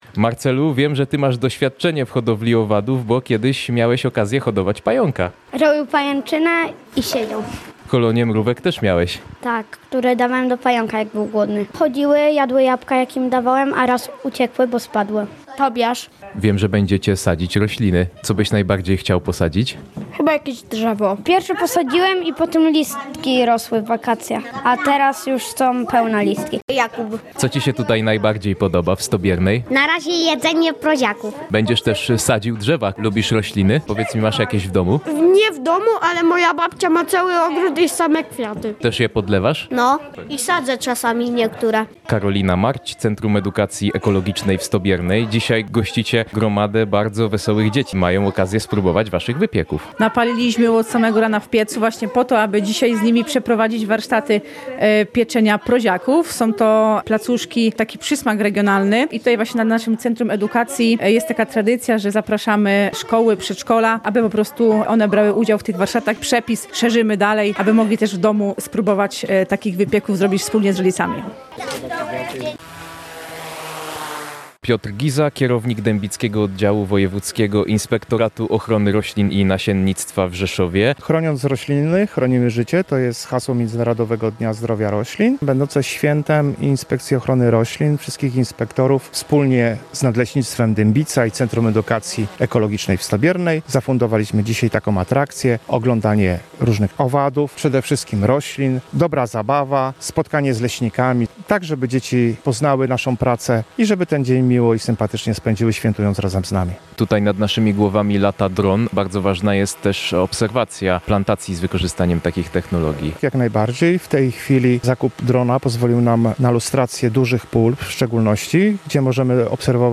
Dzien-Zdrowia-Roslin-relacja.mp3